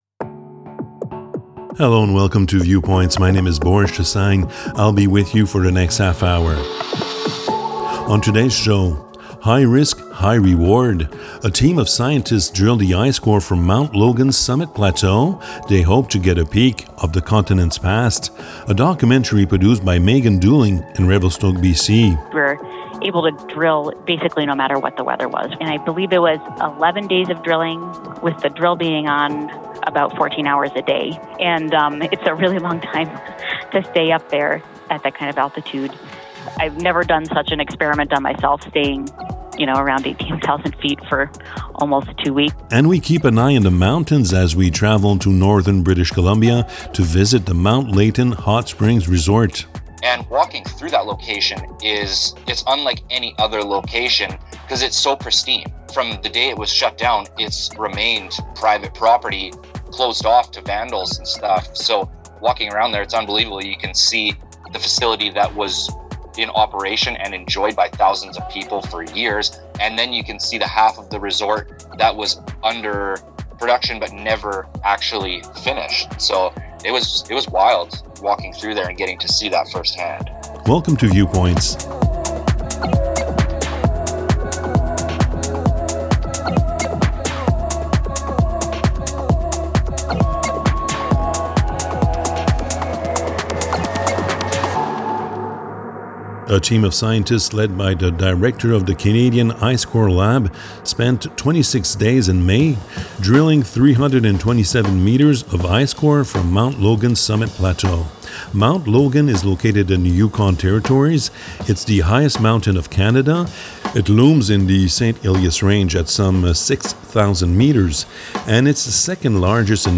Viewpoints, season 1, Ep. 18 Viewpoints is a half-hour magazine aired on 30 radio stations across Canada.